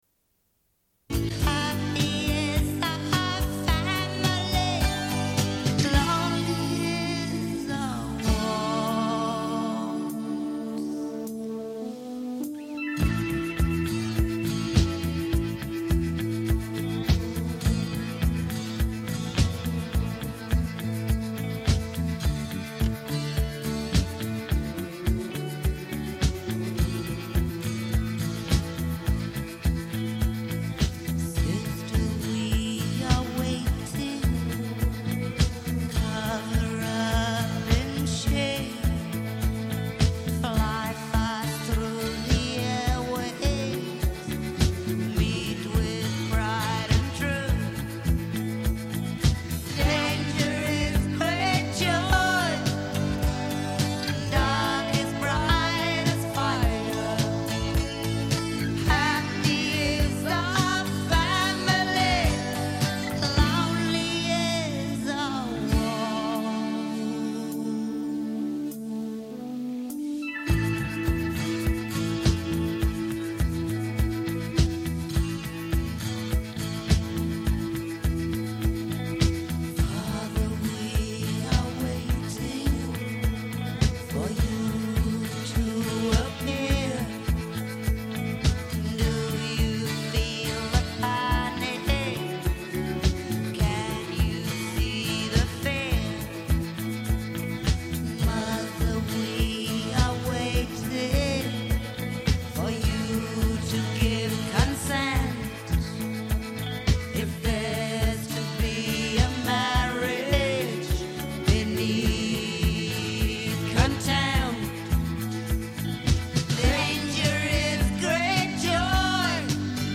Une cassette audio, face B28:29
Suite de l'émission sur la militante des droits humains Rigoberta Menchù, Prix Nobel de la Paix 1992. Lecture d'un article et extraits de son livre Moi Rigoberta.